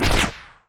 etfx_shoot_rocket03.wav